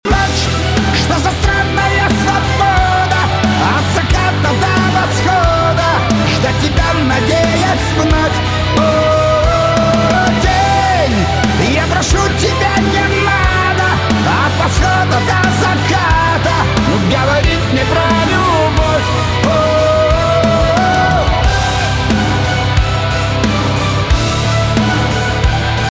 Жанр: Эстрада